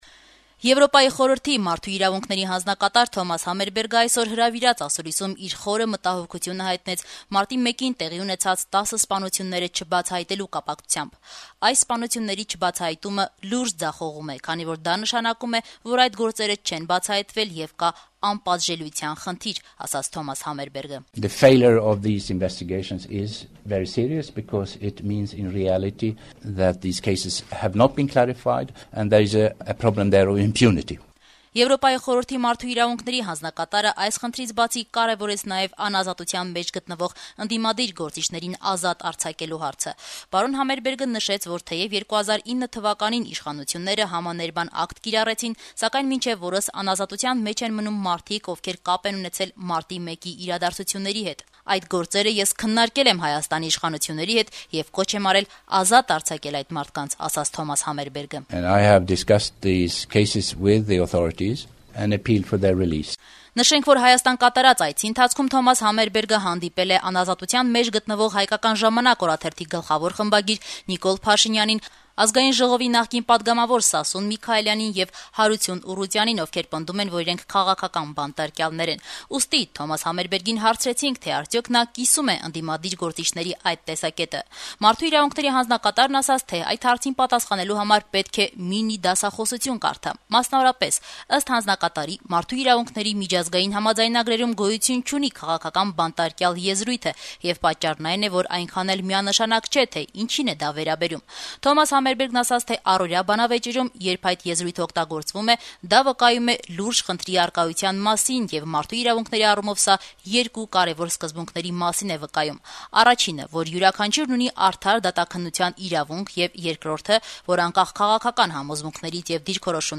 Եվրախորհրդի Մարդու իրավունքների հանձնակատար Թոմաս Համարբերգը մամուլի ասուլիսում: Երեւան, 21-ը հունվարի, 2011թ.